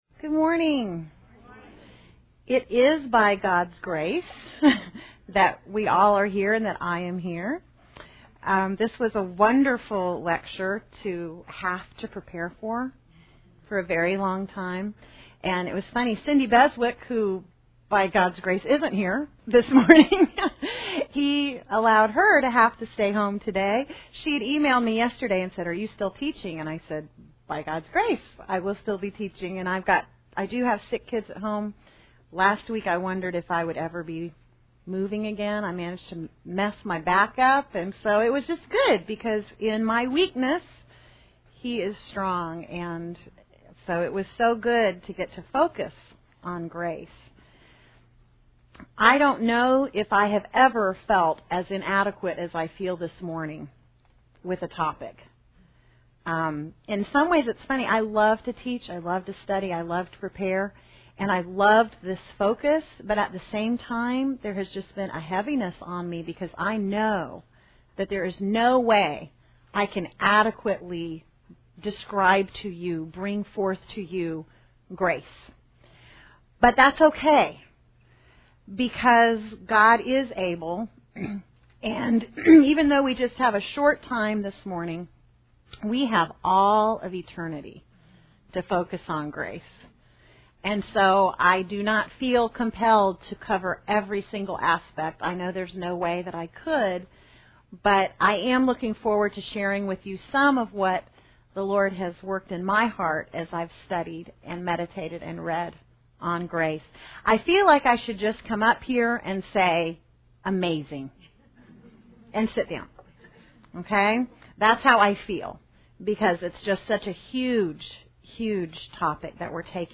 Women Women - Bible Study - The Attributes of God Audio ◀ Prev Series List Next ▶ Previous 14.